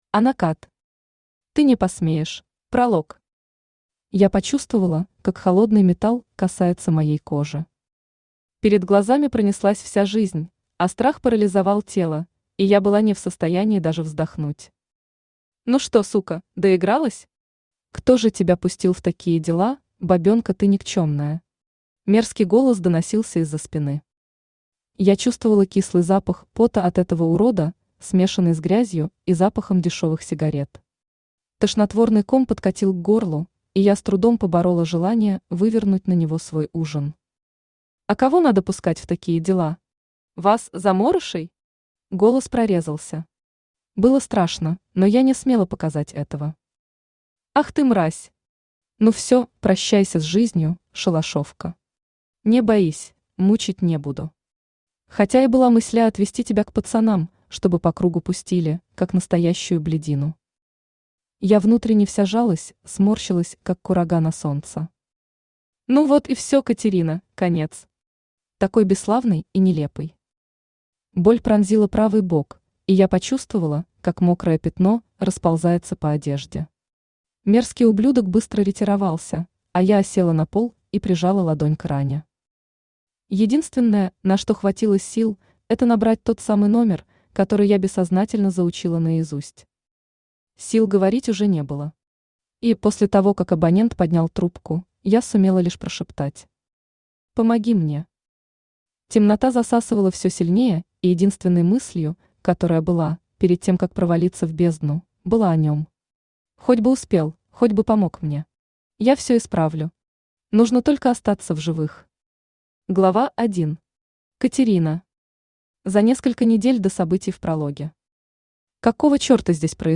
Аудиокнига Ты не посмеешь | Библиотека аудиокниг
Aудиокнига Ты не посмеешь Автор Ана Кад Читает аудиокнигу Авточтец ЛитРес.